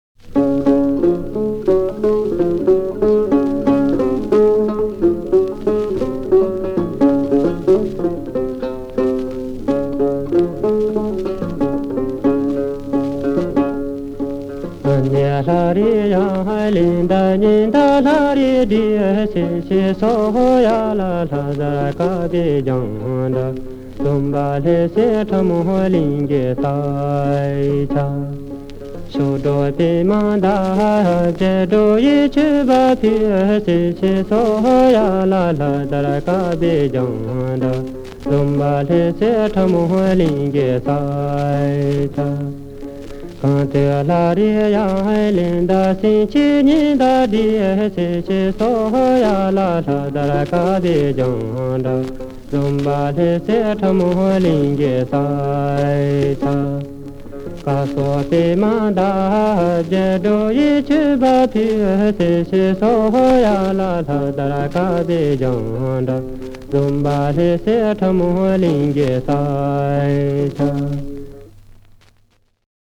7 NU – Folk Song (No. 1)
These tiny records – possibly the smallest vinyl records that can still be played with a stylus – have an adhesive backside to affix to either a letter to postcard.